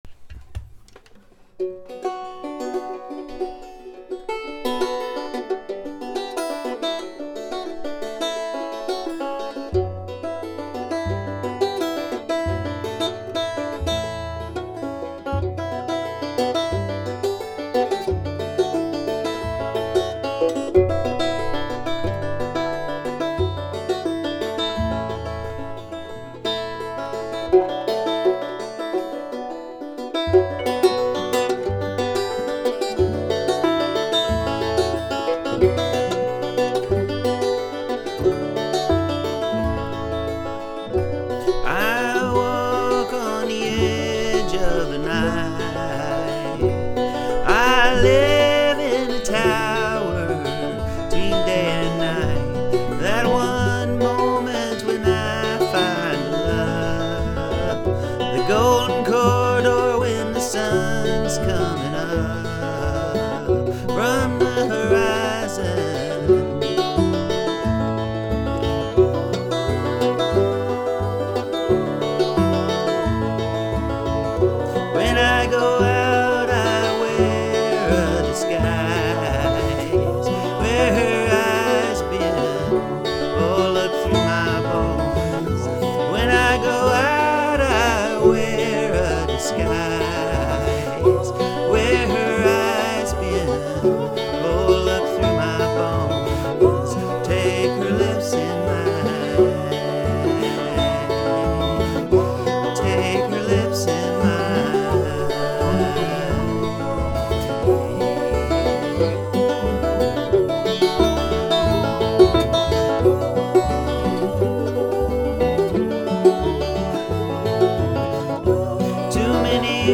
When I Go Out I Wear a Disguise, banjo, guitar and midi organ and singing